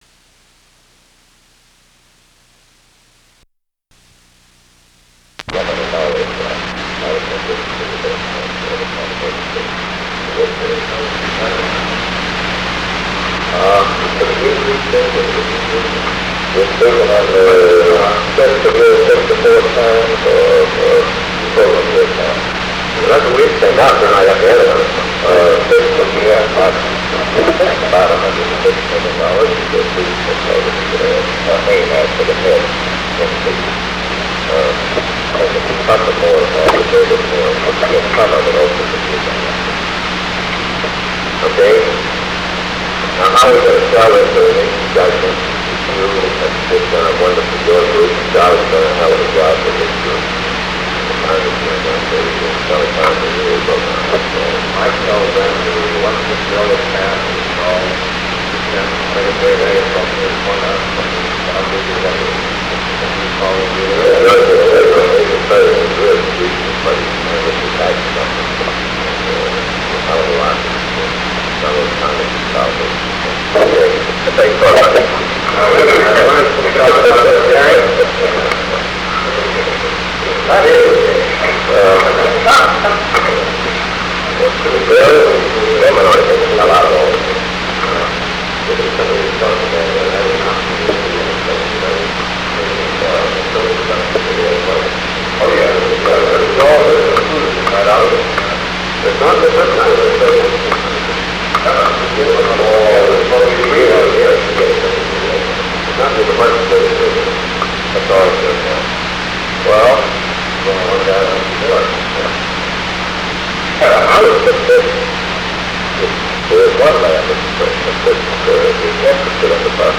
Secret White House Tapes | John F. Kennedy Presidency Meeting about Aid to Yugoslavia Rewind 10 seconds Play/Pause Fast-forward 10 seconds 0:00 Download audio Previous Meetings: Tape 121/A57.